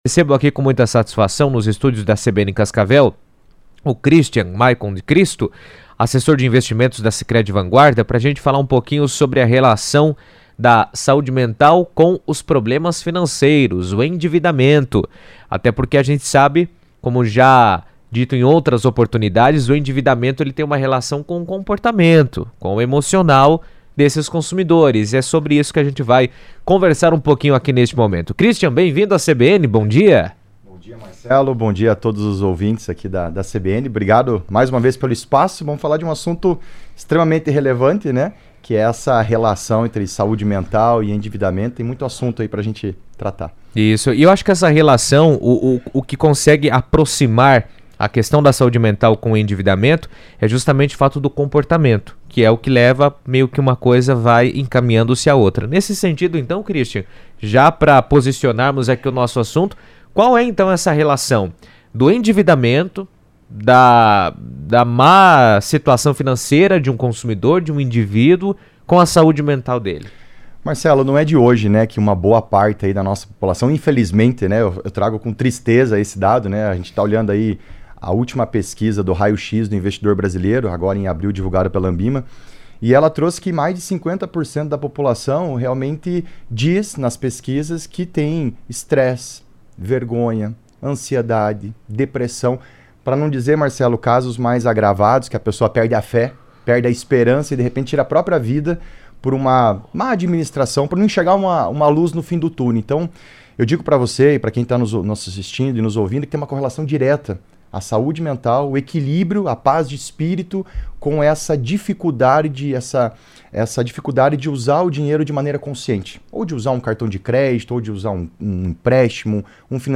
Uma pesquisa da Confederação Nacional do Comércio de Bens, Serviços e Turismo (CNC) revelou que 72% dos brasileiros têm a saúde emocional afetada por problemas financeiros, evidenciando a relação direta entre dificuldades econômicas e bem-estar psicológico. Em entrevista à CBN